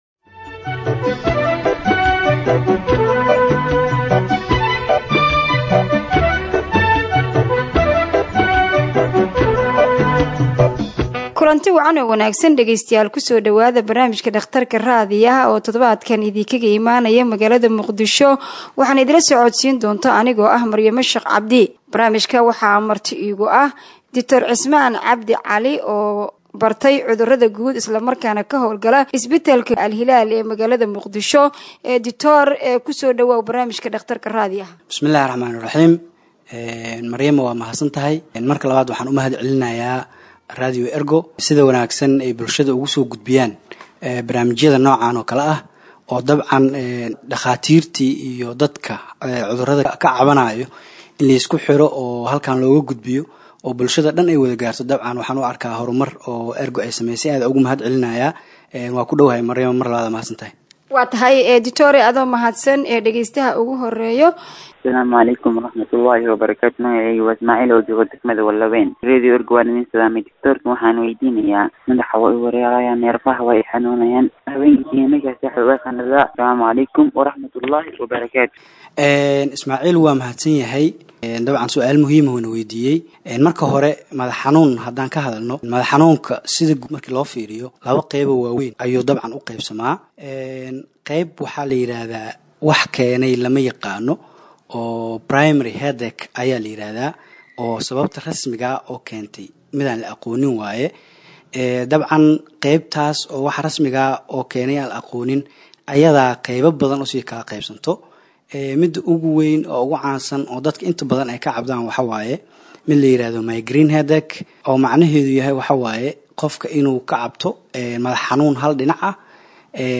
HEALTH EXPERT ANSWERS LISTENERS’ QUESTIONS ON COVID 19 (66)